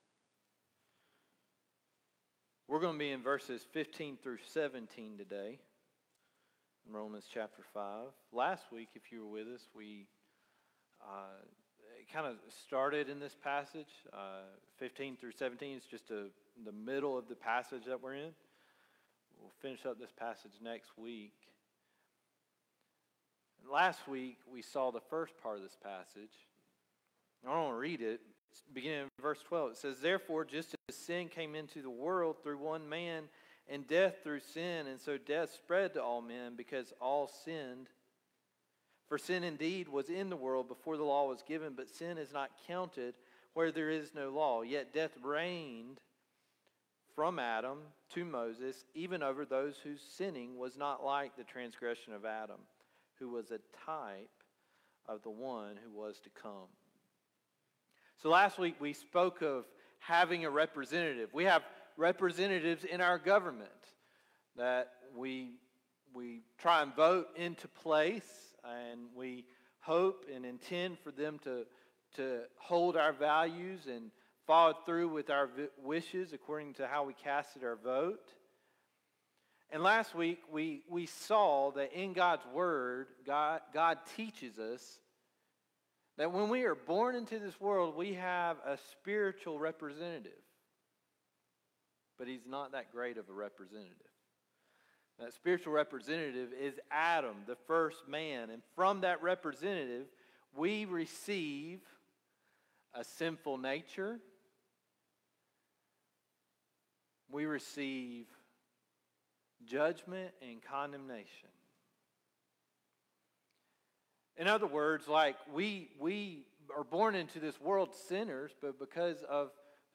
Sermons | Eastside Baptist Church
Guest Speaker